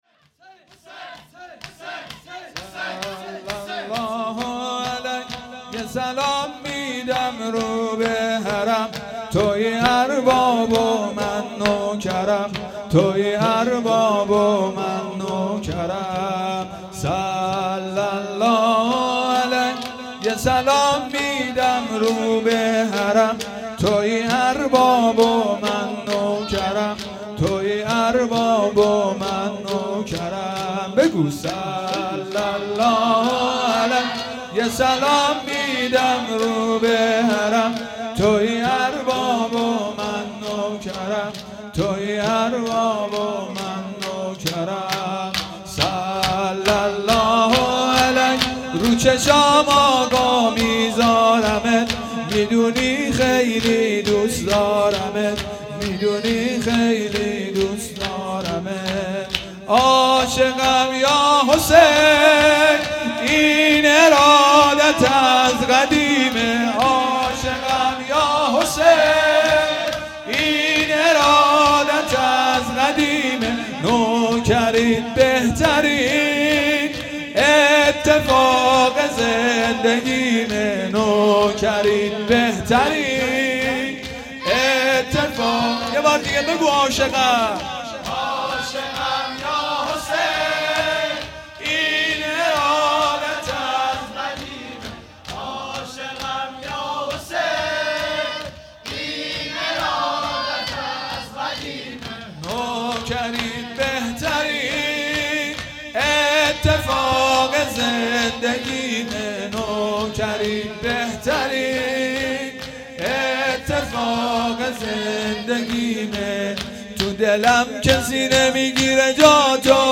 شور
شب هفتم محرم الحرام 1441